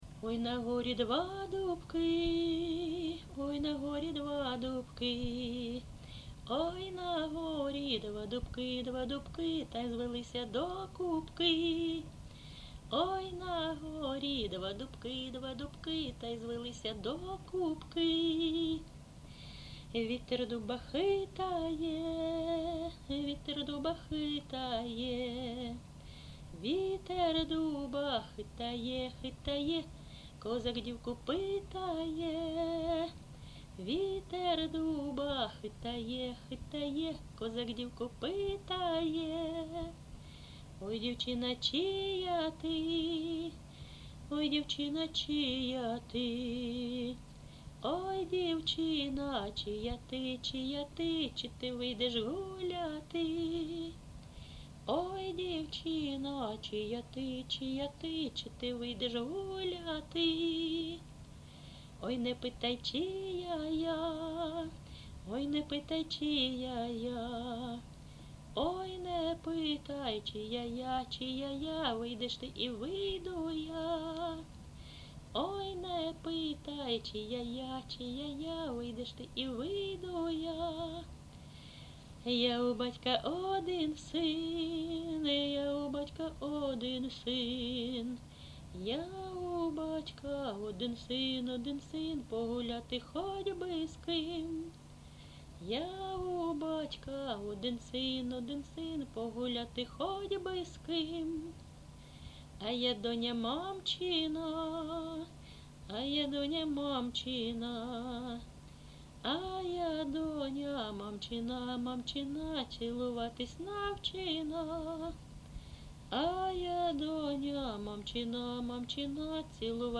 ЖанрПісні з особистого та родинного життя
Місце записум. Ровеньки, Ровеньківський район, Луганська обл., Україна, Слобожанщина